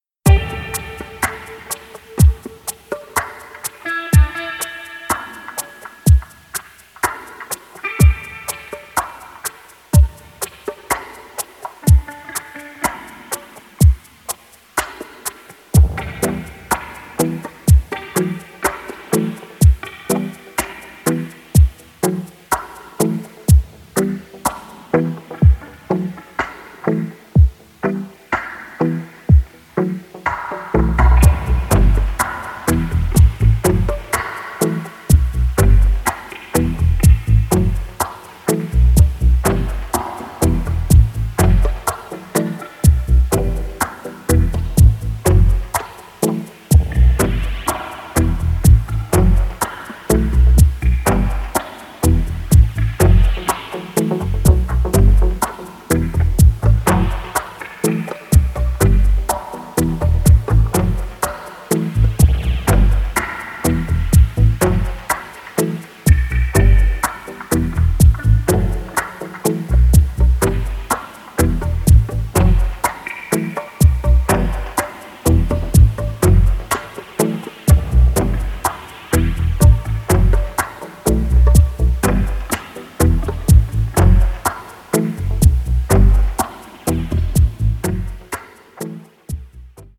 無駄無く削ぎ落とされたモダンでミニマルな音響から浮かび上がるアーシーな郷愁。